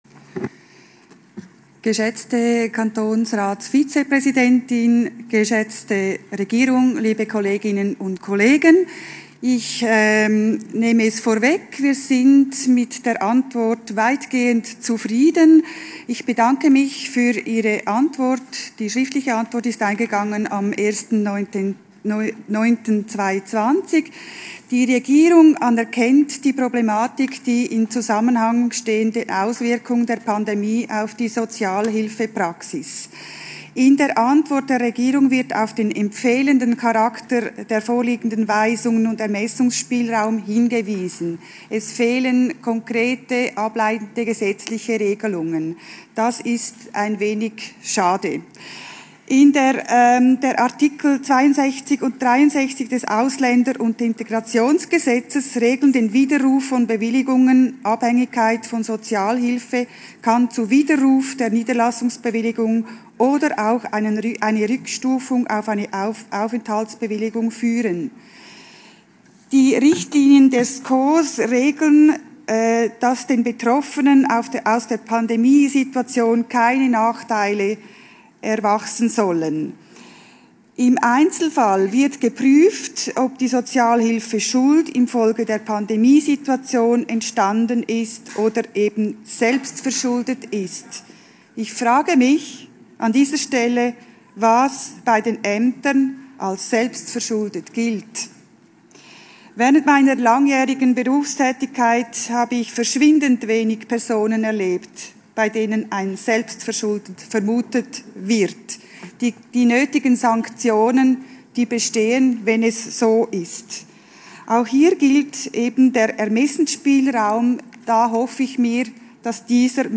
Session des Kantonsrates vom 14. bis 17. September 2020